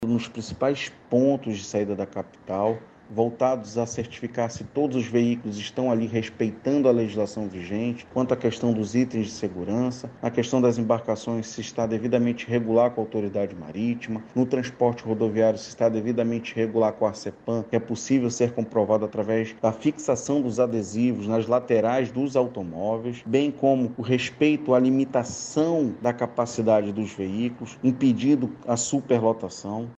SONORA-1-FISCALIZACAO-FERIADO-ARSEPAM-.mp3